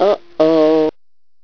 argl.mp3